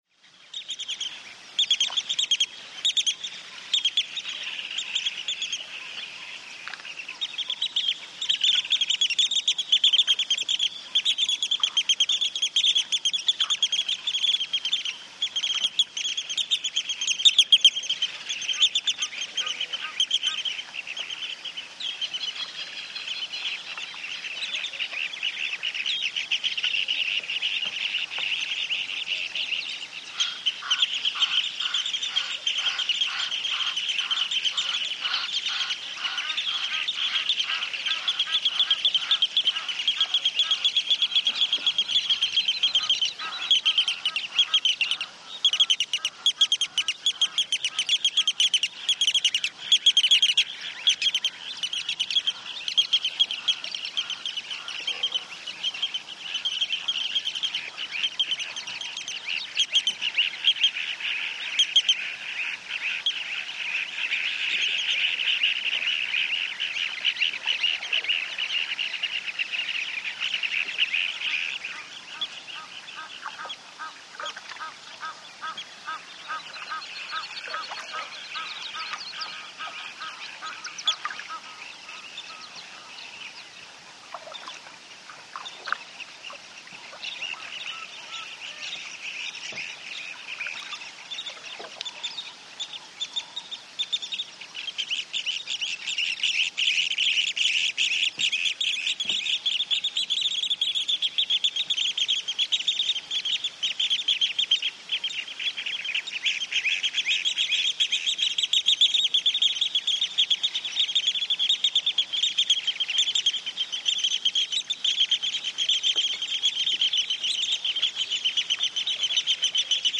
CROWD - FOREIGN AFRICA: MASAI: Distant chanting, goat bells, goats, cattle bellows, medium wind background Masai settlement.